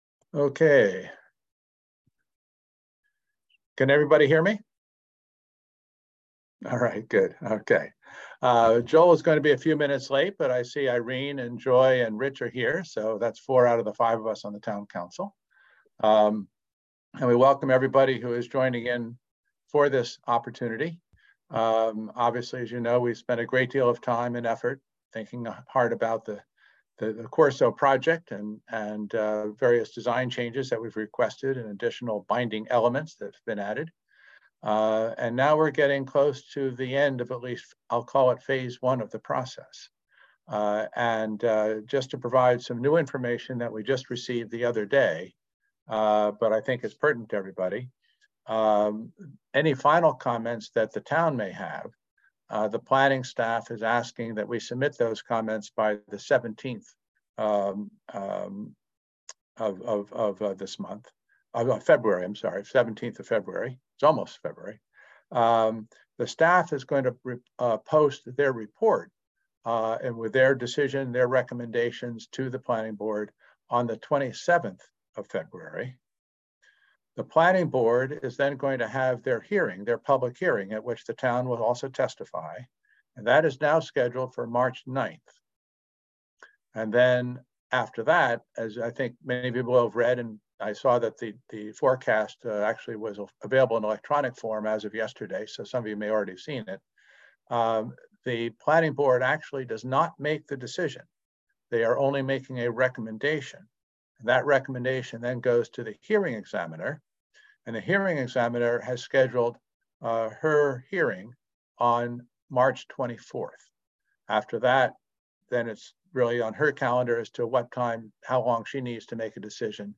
On January 30, the Town hosted a public forum to hear from residents about the final rezoning plan for 7100 Connecticut Avenue.